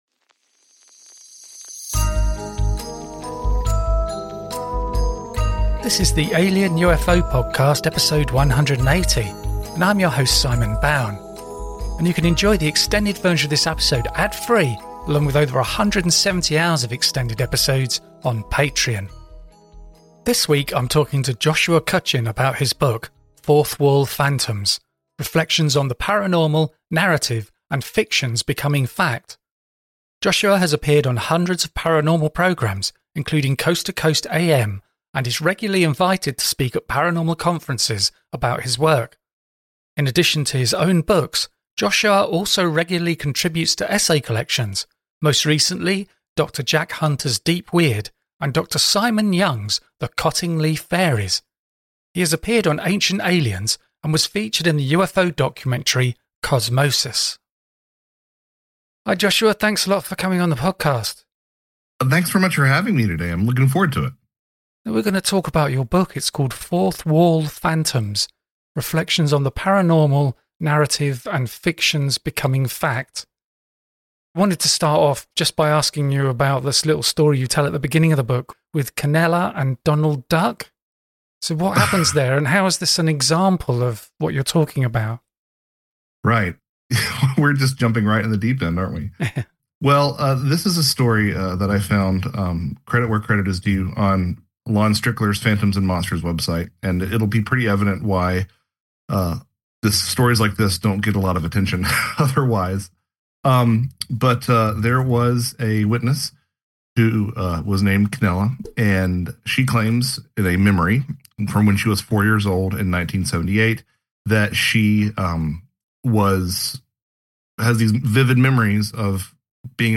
Throughout this engaging conversation, we examine the implications of these experiences on our perception of consciousness and reality. How do these narratives influence our beliefs in extraterrestrial encounters and the unexplained phenomena surrounding them?